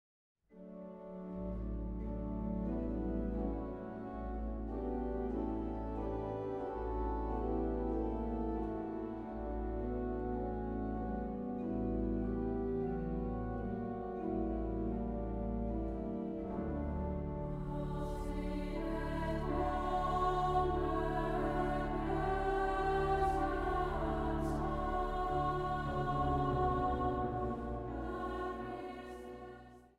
trompet
cello
orgel
piano.
Zang | Gemengd koor
Zang | Jongerenkoor